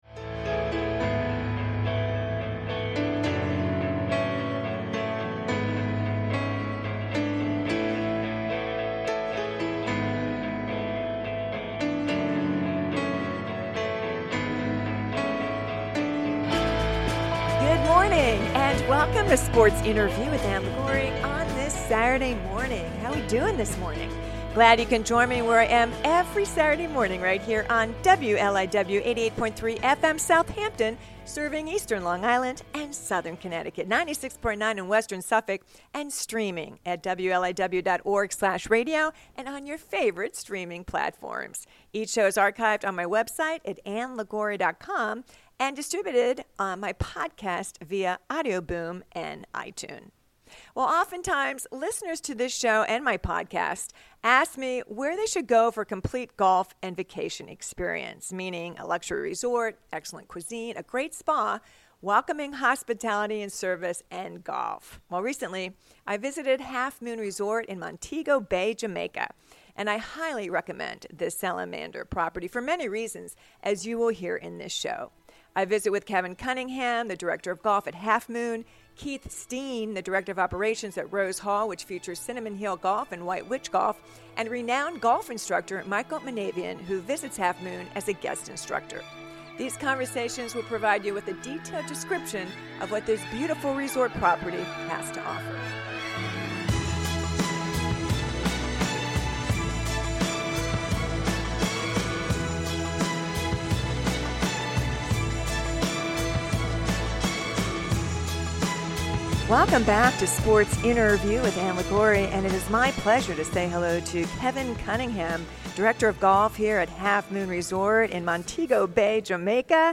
broadcasts from Half Moon in Montego Bay, Jamaica